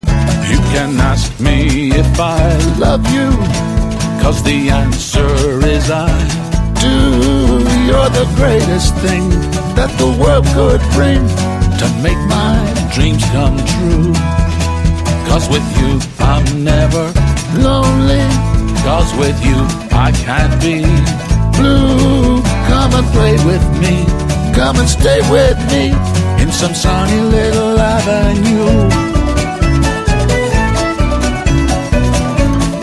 bass guitar
Uilleann pipes.